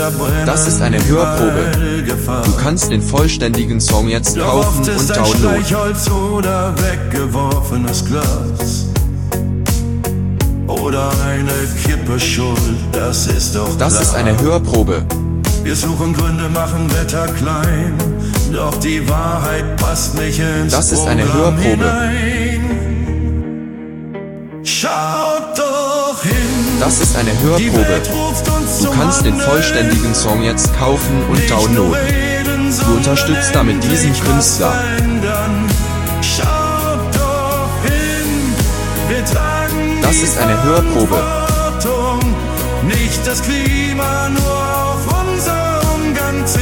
👉 Ein Song voller Hoffnung, Mut und Aufruf zum Handeln.